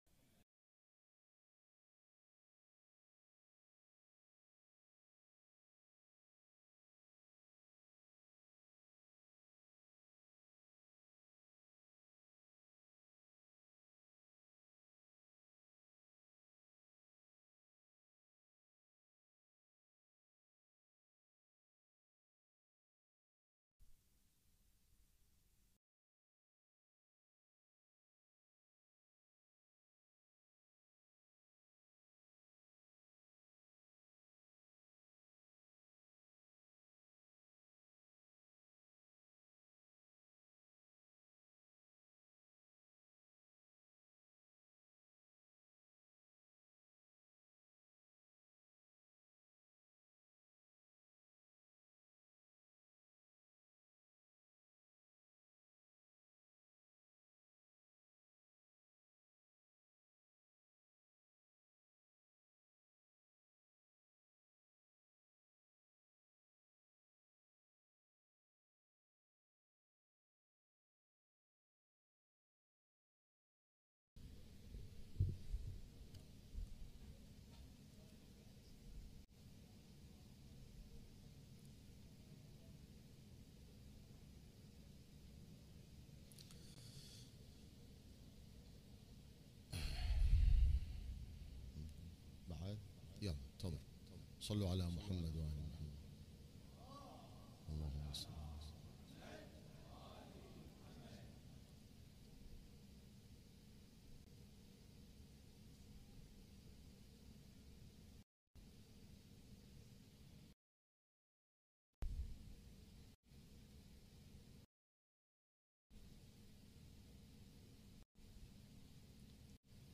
الان-مباشرة-ليلة-٩-محرم-١٤٤٦هـ-هيئة-الزهراء-للعزاء-المركزي-في-النجف-الاشرف.mp3